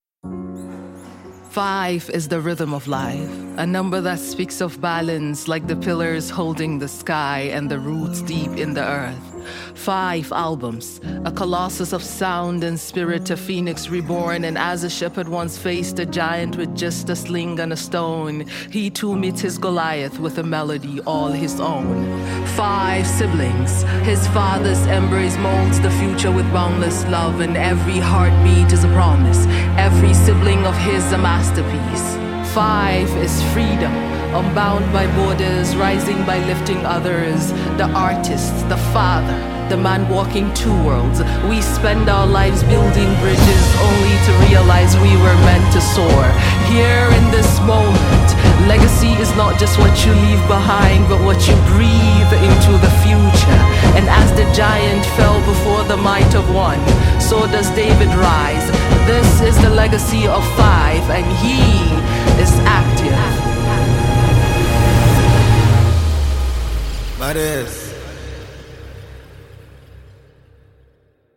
AfroBeats | AfroBeats songs
spoken word artist